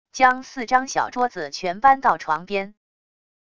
将四张小桌子全搬到床边wav音频生成系统WAV Audio Player